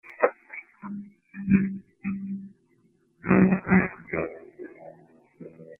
Here's A Few EVP's Featuring Sing Song Voices & Music
"Hear The Banjo" - Slowed Down Version